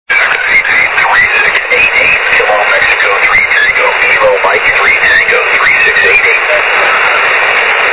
Všechny nahrávky pocházejí z FT817.